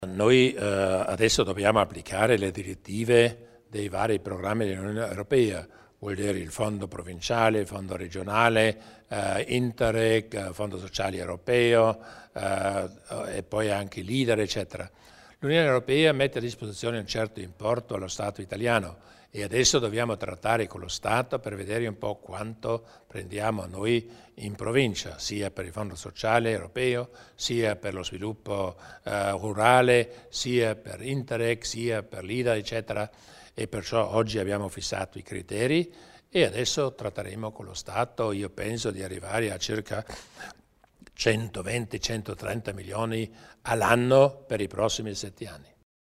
Il Presidente Durnwalder illustra le possibili collaborazioni in ambito europeo